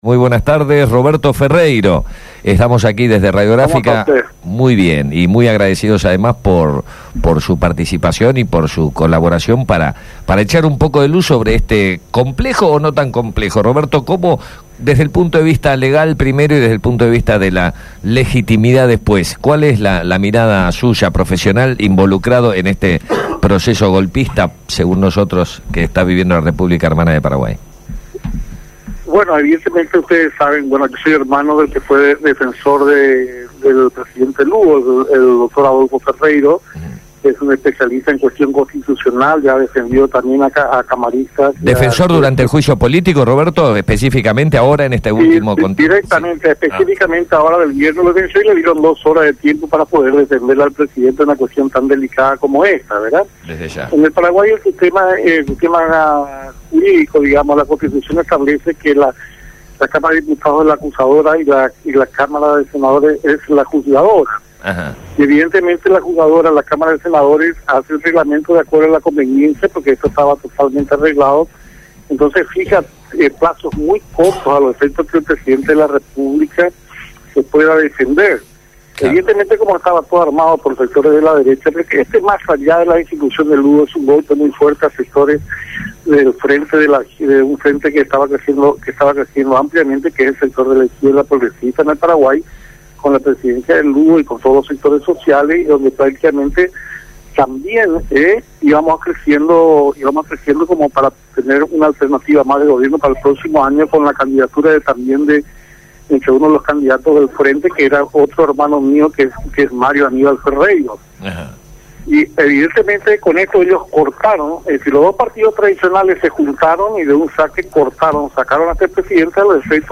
Comunicación con Fm Candela de Asunción del Paraguay